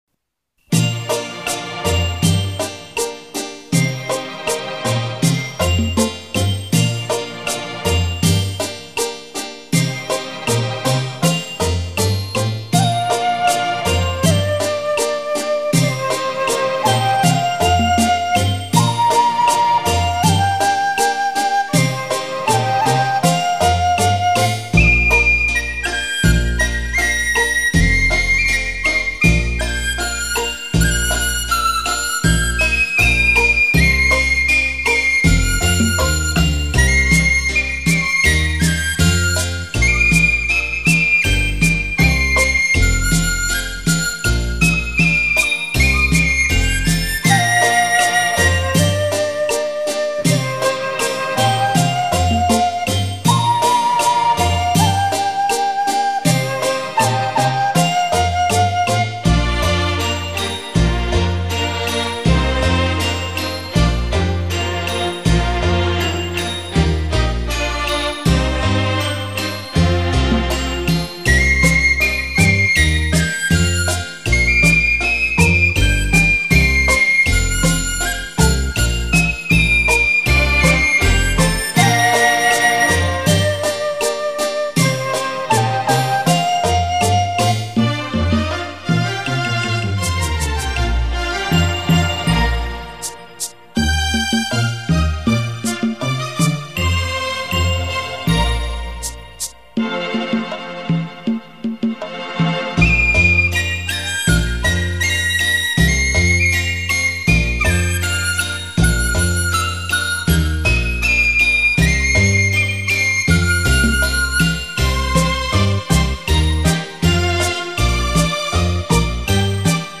纯音乐
经国乐演奏，流露出思古幽情